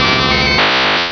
Cri de Raichu dans Pokémon Rubis et Saphir.